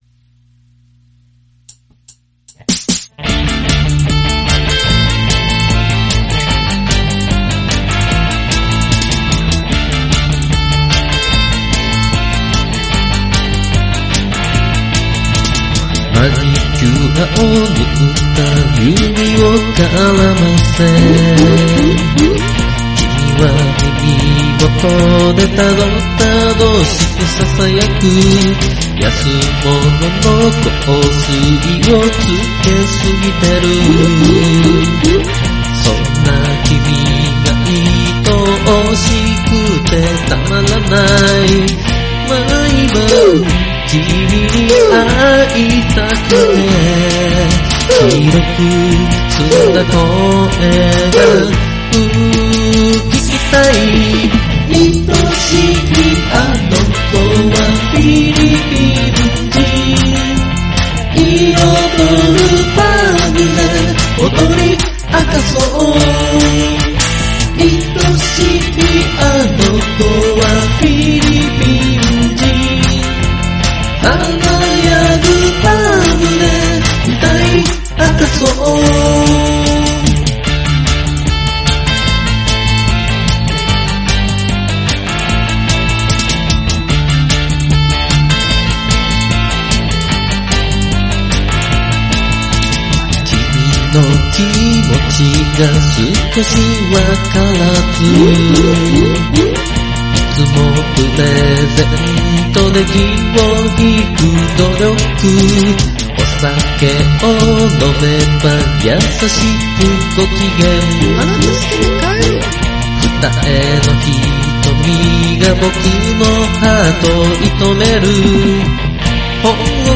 Vocal、Chorus、Bass、Keyboard、E.guitar
ライブで盛り上がれるような曲を作りたくて作りました。